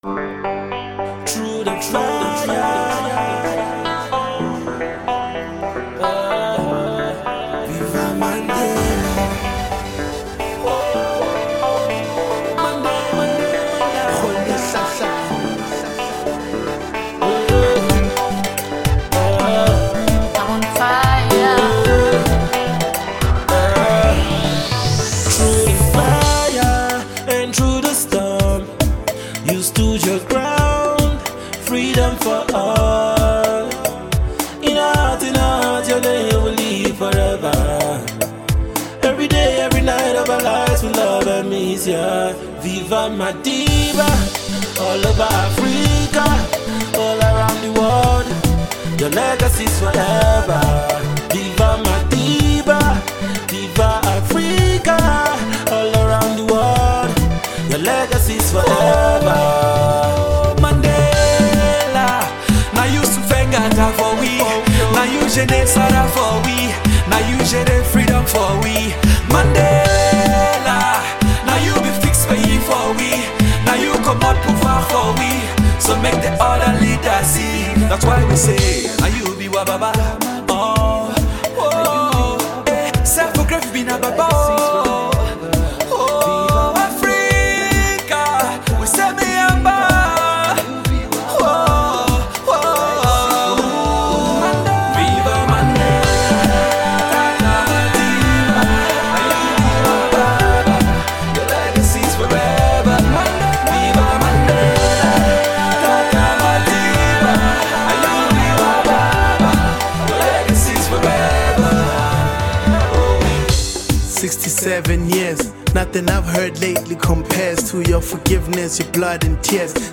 rapper
tribute track